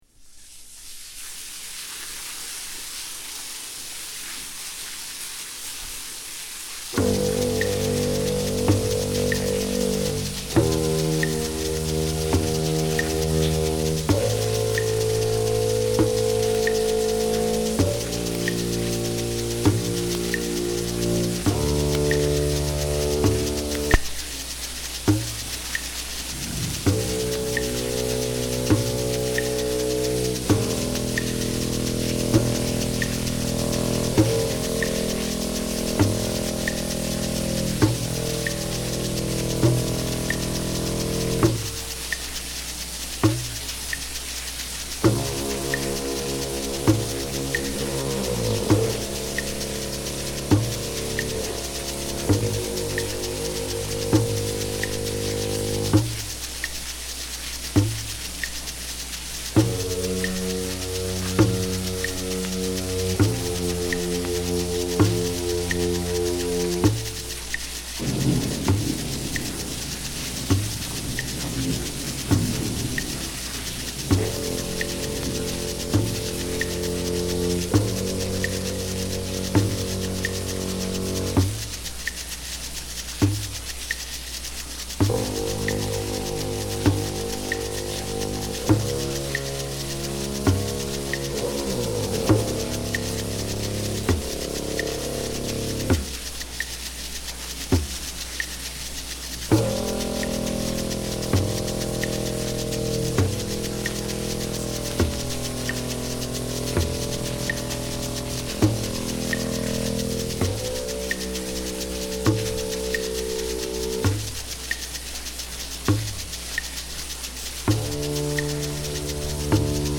Home > Ambient